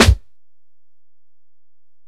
Snare (41).wav